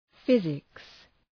Προφορά
{‘fızıks}